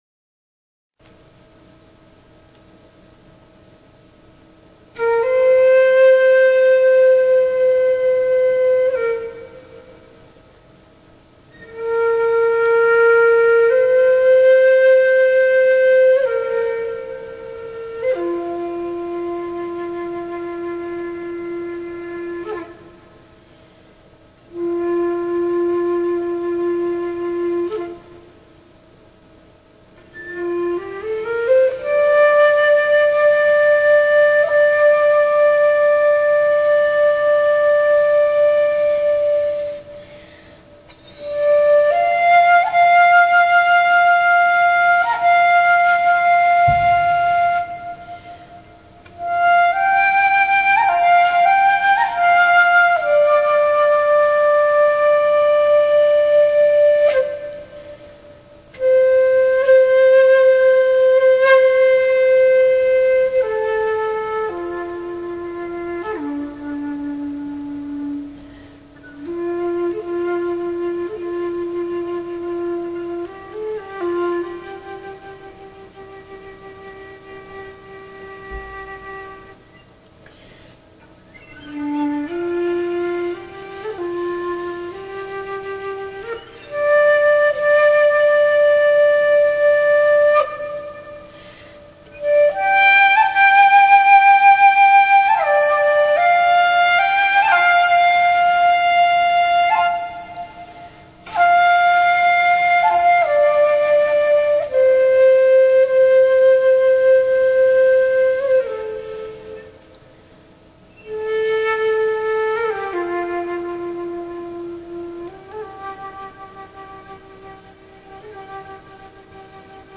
Talk Show Episode, Audio Podcast
The Elohim share how nodes of energy/frequency created space and time where none existed previously. As they communicated this information they connected up with the energies of listeners and at the end of the show, people had the opportunity to make silent requests.